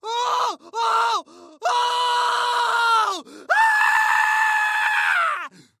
Звуки мужские
Звук испуганного мужчины, вырывающегося в ужасе